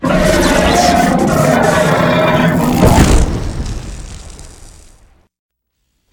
combat / enemy / droid / bigdie2.ogg
bigdie2.ogg